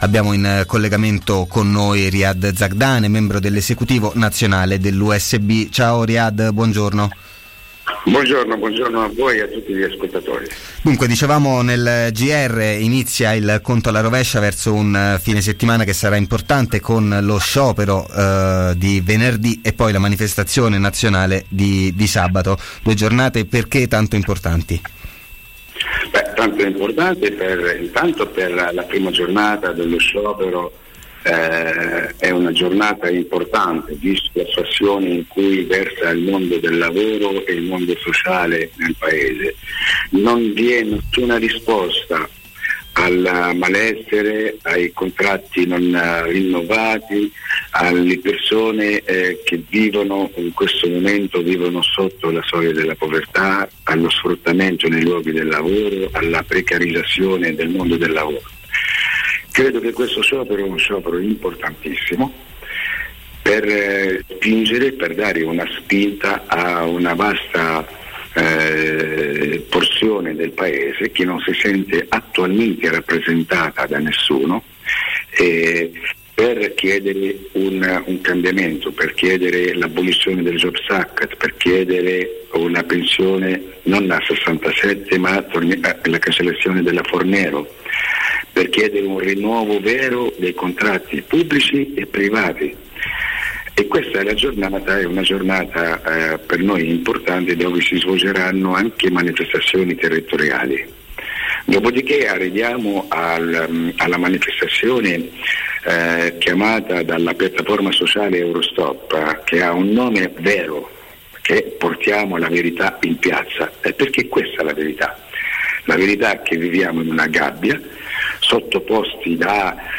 Verso le mobilitazioni del 10 e 11 novembre: intervista